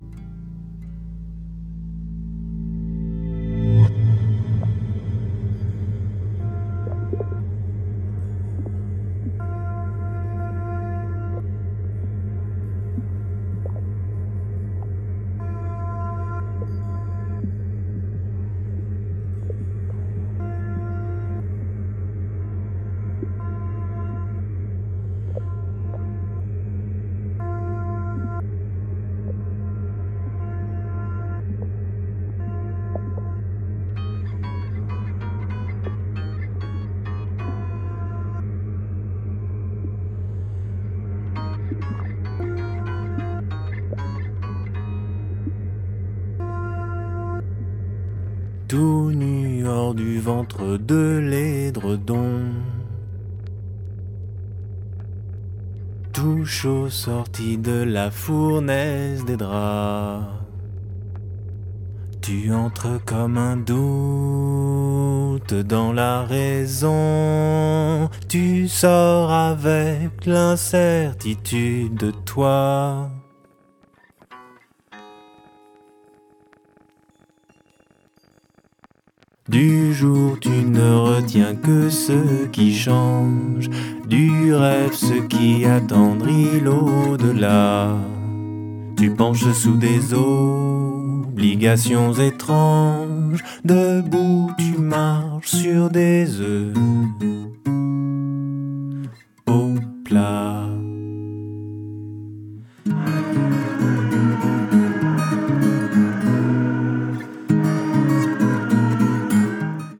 フランスのSSW
ギターの弾き語りを軸としたメランコリックなサウンドに変化はありませんが